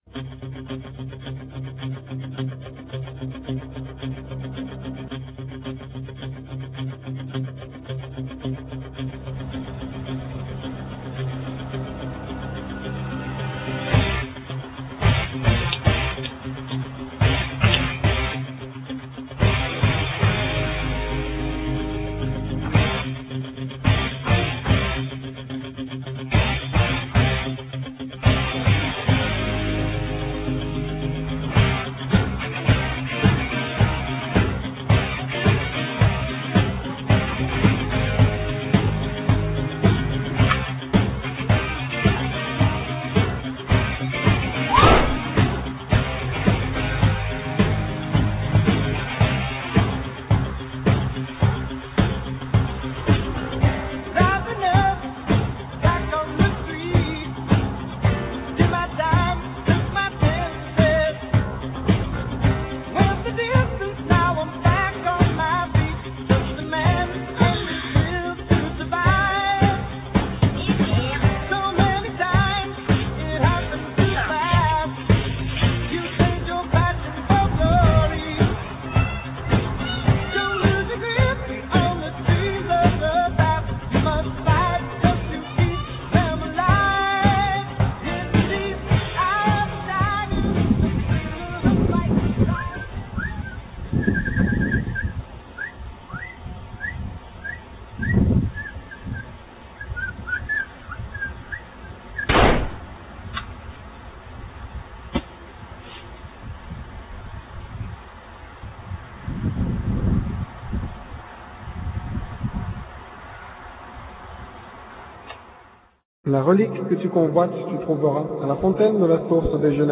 Nous avons volontairement choisi de passer la plupart des vidéos streaming en mono car lors du tournage et du montage du film la stéréo n'a pas été utilisée.
- Audio : Moyen